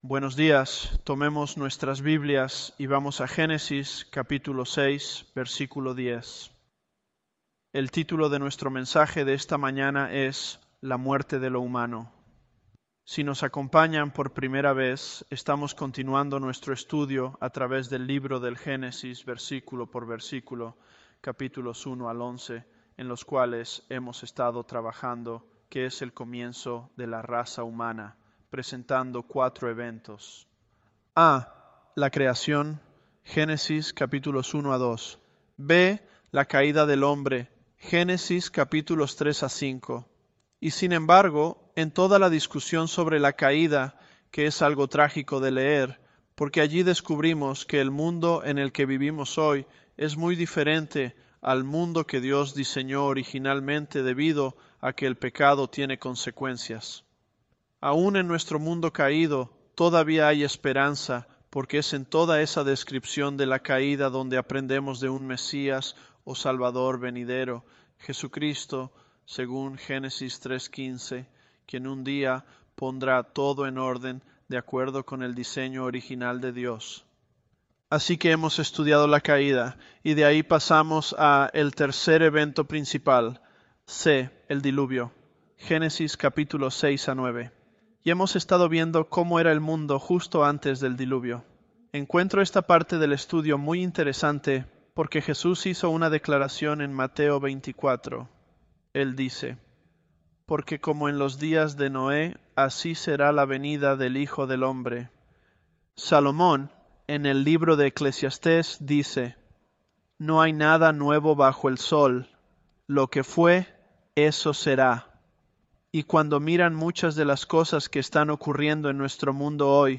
ElevenLabs_Genesis-Spanish028.mp3